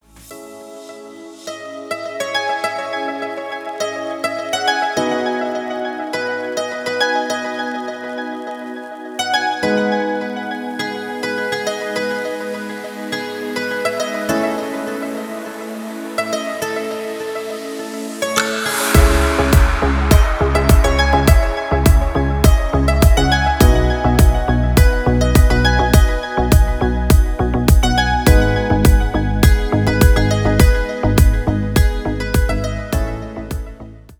• Качество: 320, Stereo
deep house
Electronic
спокойные
без слов
красивая мелодия
релакс
Chill
Стиль: deep house.